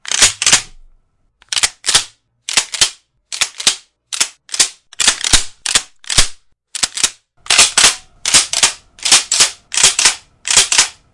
Shotgun Action Cycling
描述：A Remington 1100 cycling without any shells.
标签： cock rifle reload gun shotgun load
声道立体声